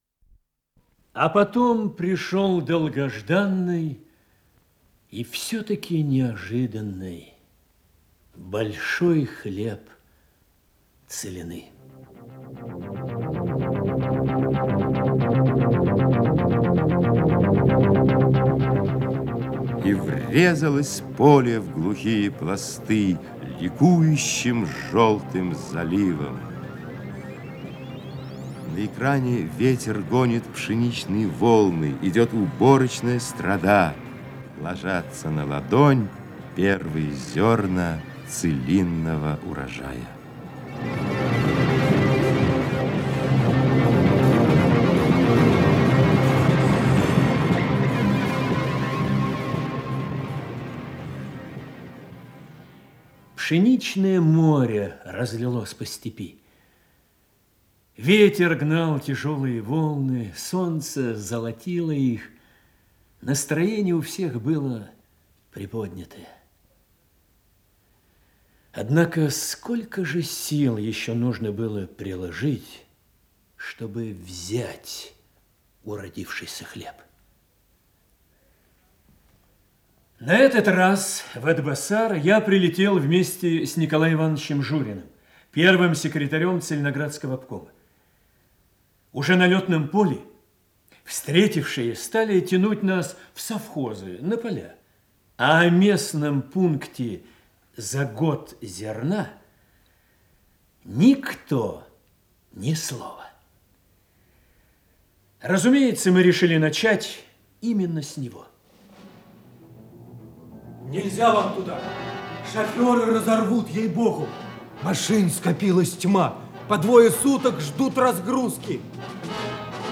Исполнитель: Артисты Государственного академического Малого театра СССР
Радиокомпозиция спектакля Государственного академического Малого театра